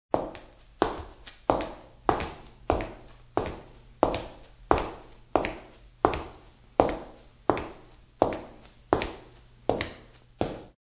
HEELS STEADY N CONFIDENT MP3 SOUND
Ambient sound effects
HEELS_STEADY_N_CONFIDENT_MP3_SOUND.mp3